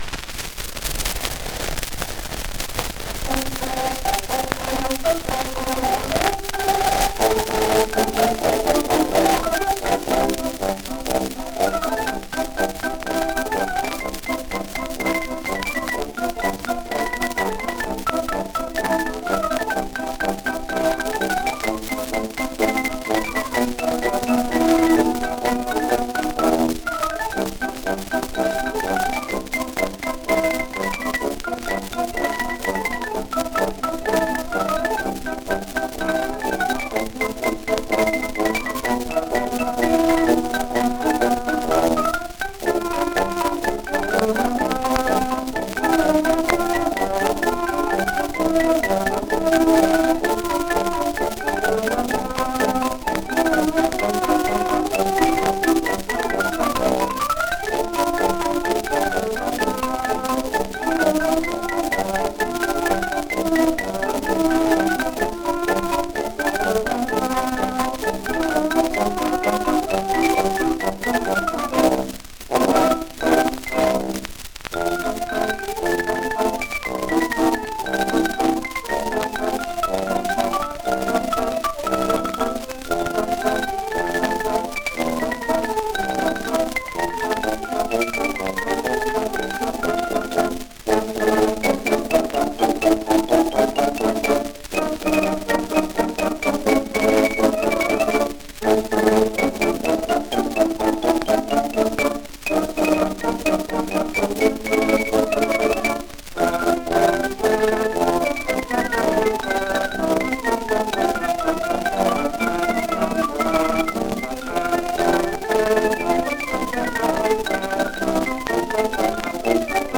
1 disco : 78 rpm ; 27 cm Intérprete
Xylophon